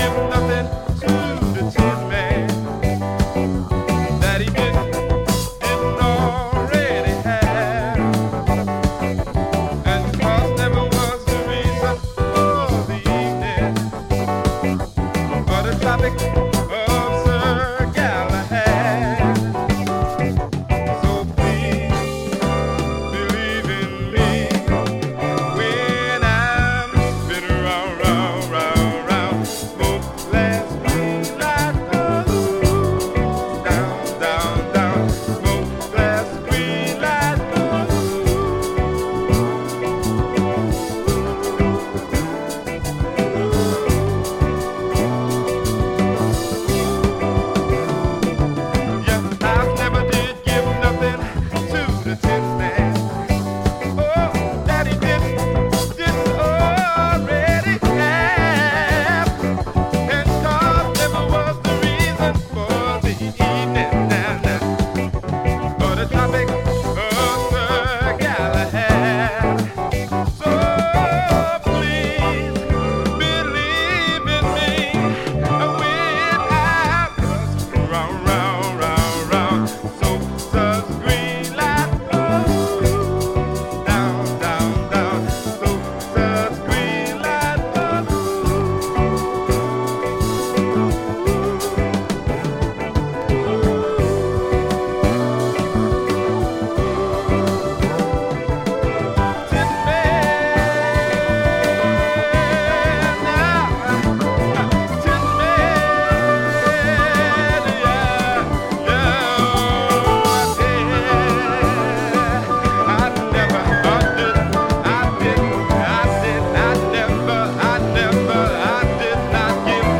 Funk and soul